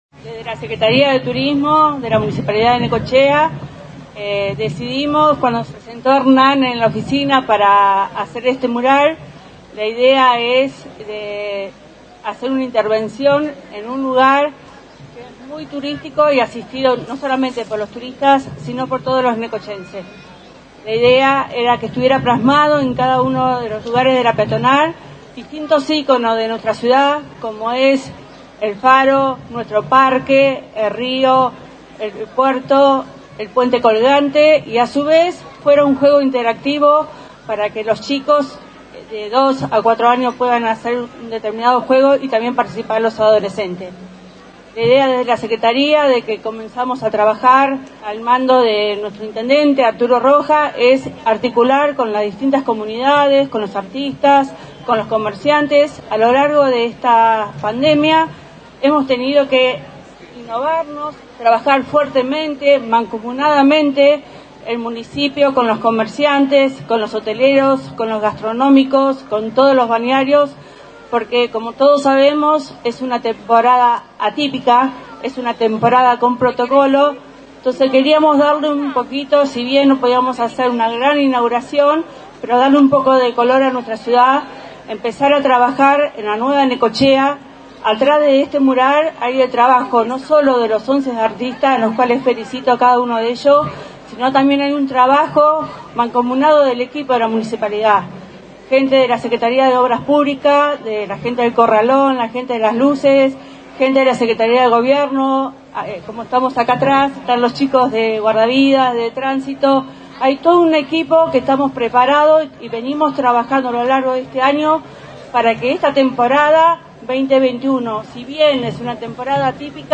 (Necochea) En un colorido acto, Rojas inauguró el paseo “Arte a tus pies” en la Peatonal 83
Cayendo la tarde de este martes, el intendente Arturo Rojas dejó inaugurado oficialmente el paseo “Arte a tus pies” en plena Peatonal 83, una colorida intervención de artistas locales que viene a devolverle a la famosa calle el brillo que alguna vez supo tener.